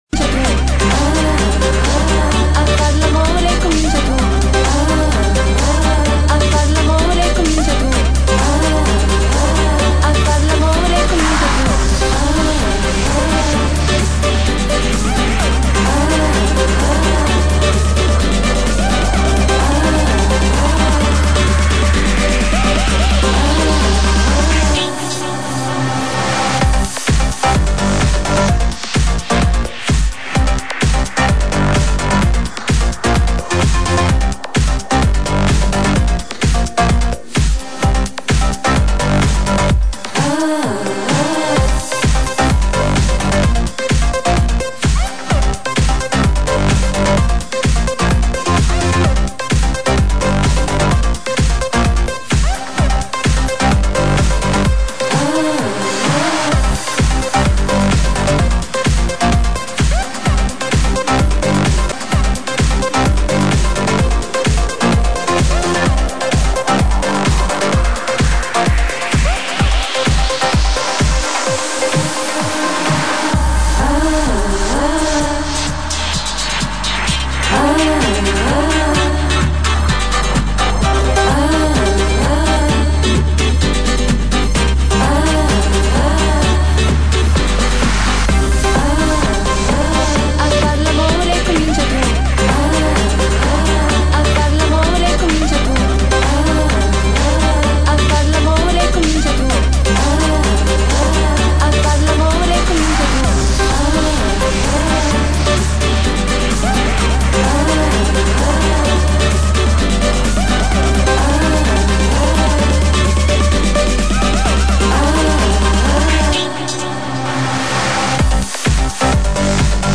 нет но в плохом качестве есть